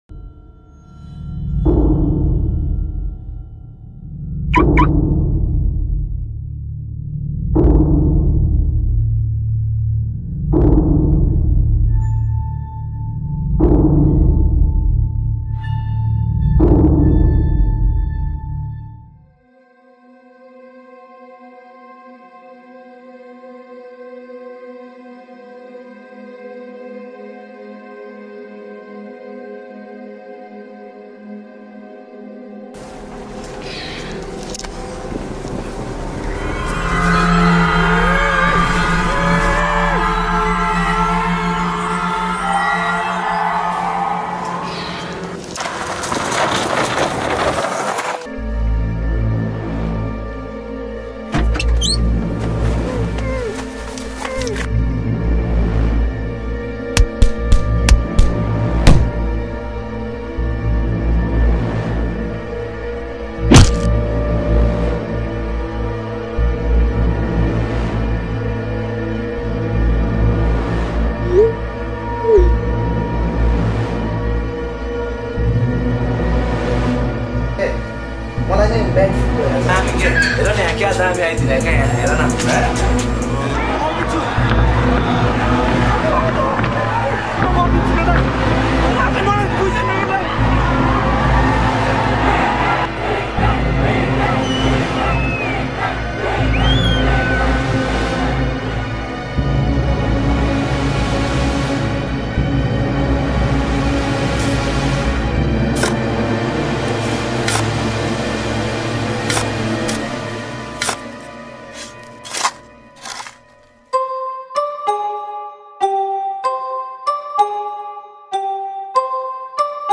# Nepali Rap Mp3 Songs Download